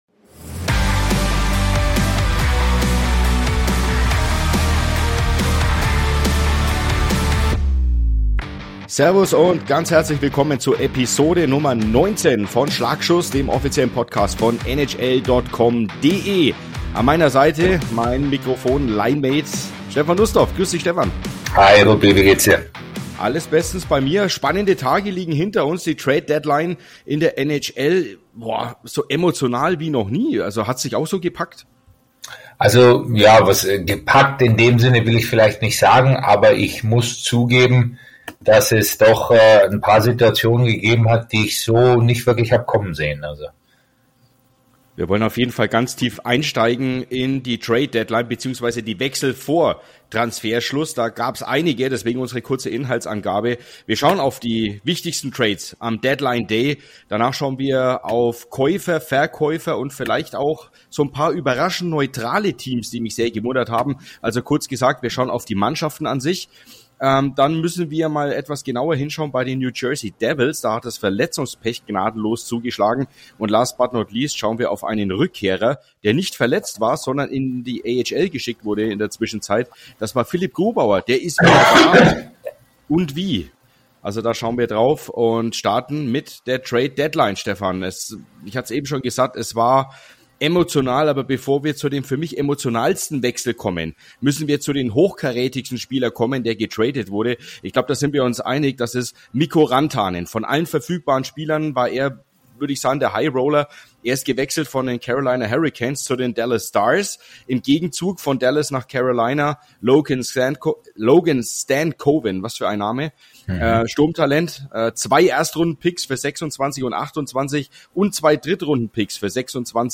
Die beiden Moderatoren